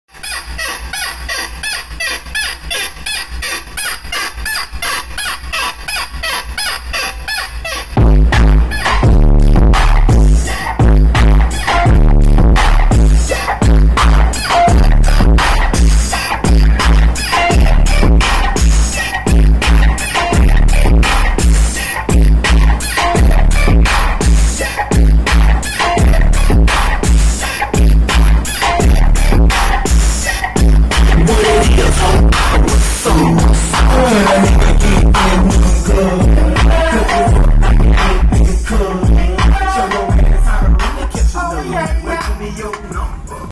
4 12"????????😈😈 distortion is due